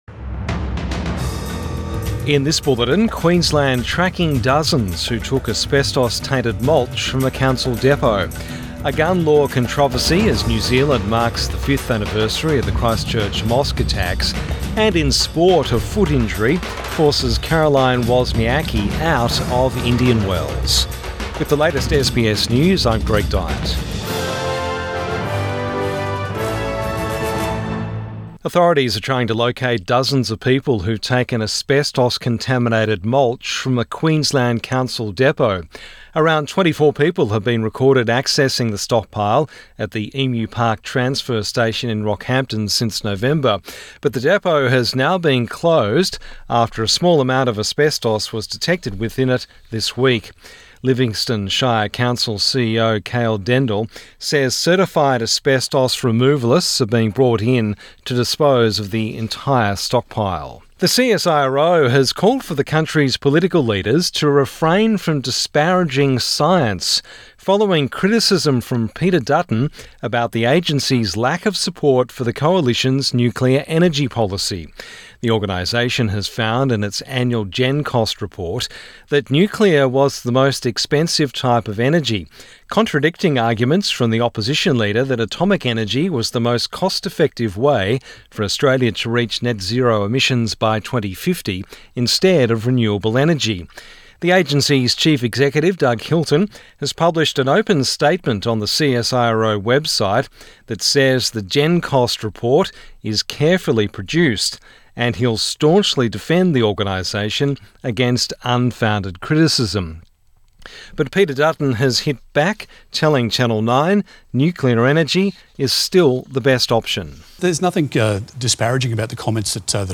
Midday News Bulletin 15 March 2024